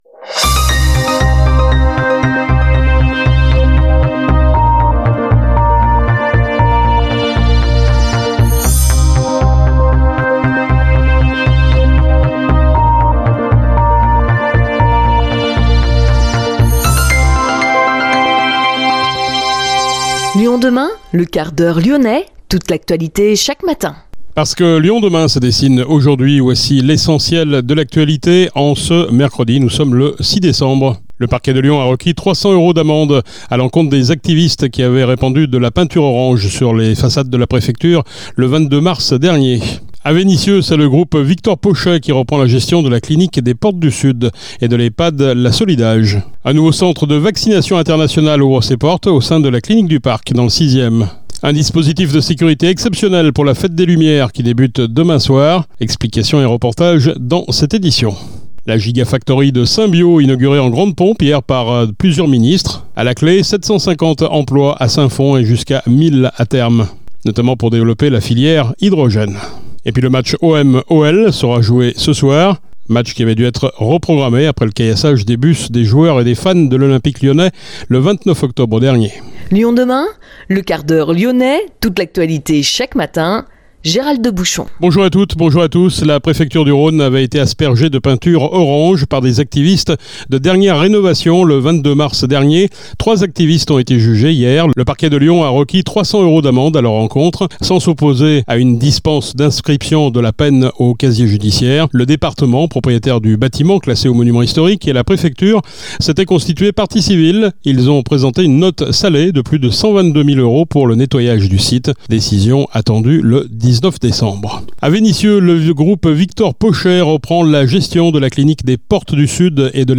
Juliette Brossard-Trignat, préfète du Rhône déléguée à la défense et la sécuritéNelson Bouard, directeur départemental de la police nationale